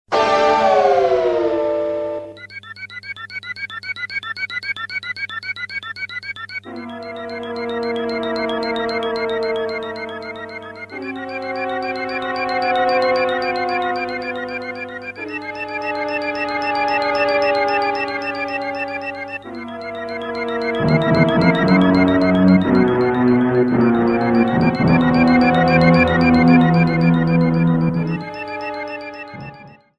Fair use music sample
30 seconds and fadeout You cannot overwrite this file.